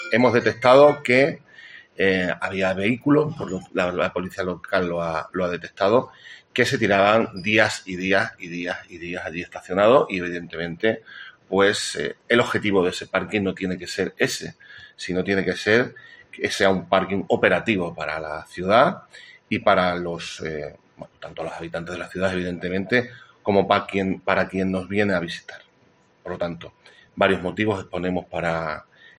David Dóniga, concejal de Interior Plasencia
Así lo ha explicado David Dóniga, concejal de interior del Ayuntamiento de Plasencia, en rueda de prensa, además daba detalles de los horarios de servicio del mismo;